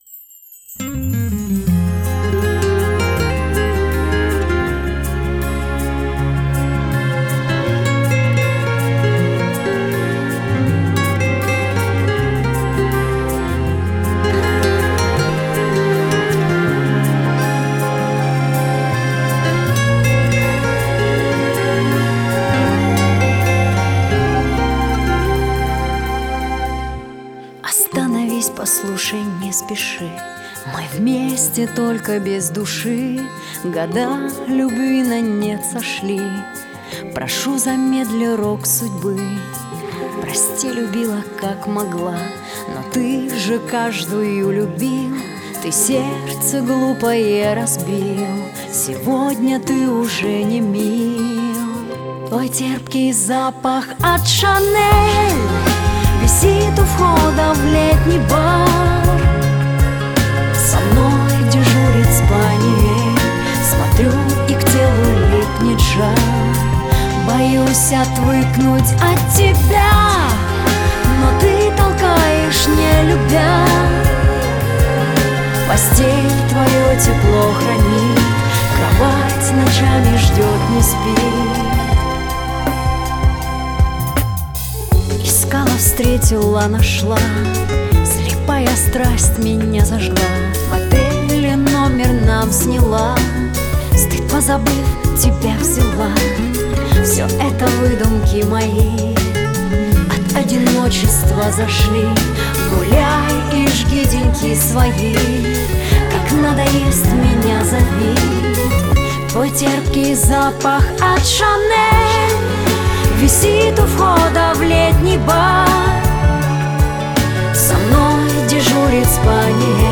Главная » Mp3 музыка » SHANSON